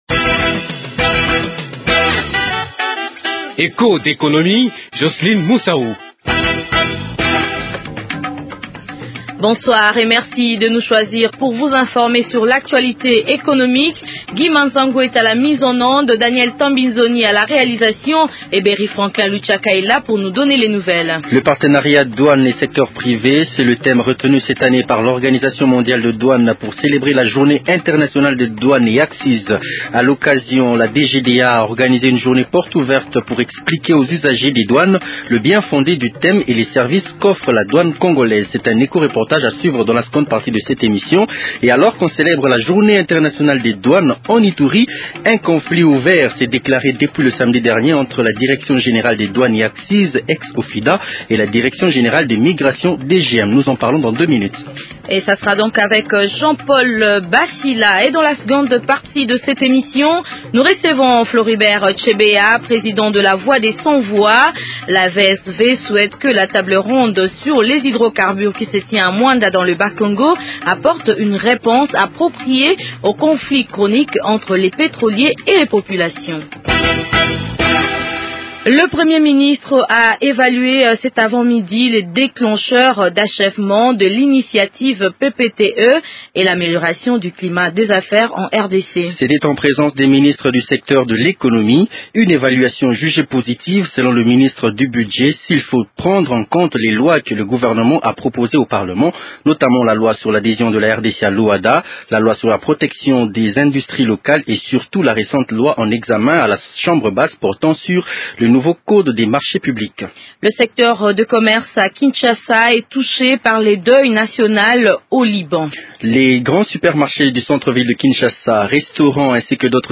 La voix des sans voix, ONG des droits de l’homme manifeste des craintes et émets en temps des espoirs quant à la tenue de cette table ronde. Floribert Chebeya, président de la VSV, est l’invité de Echos d’économie.